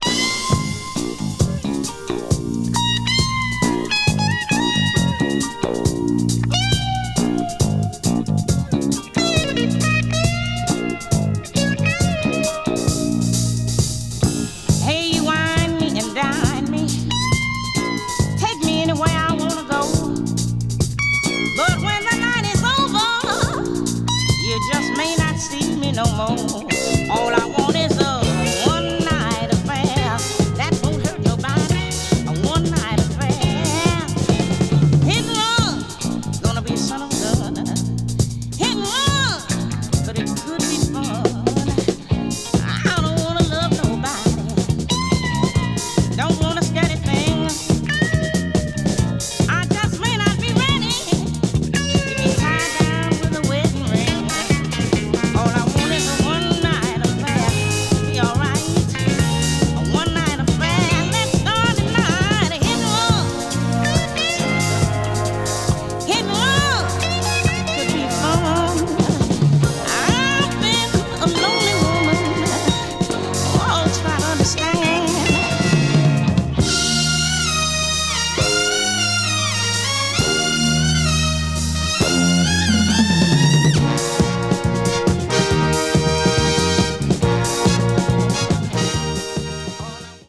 media : EX+/EX+(わずかにチリノイズが入る箇所あり)
blues   disco related   funk   r&b   soul